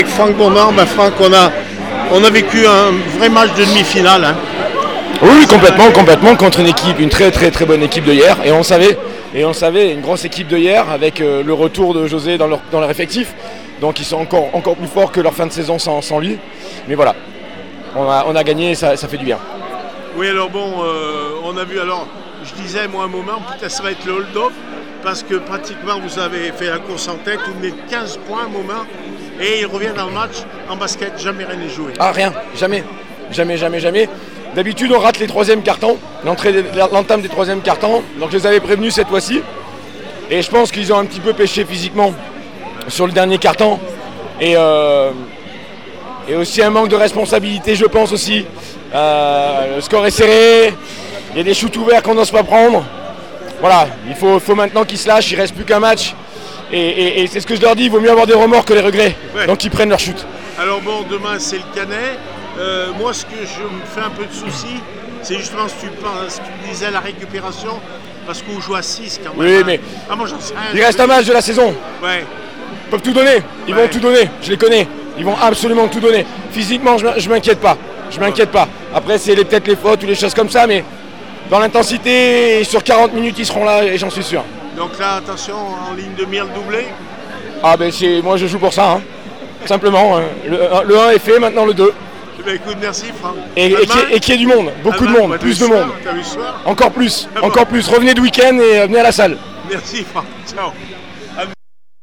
final four handi basket les aigles du Velay 71-66 Hyères réactions après match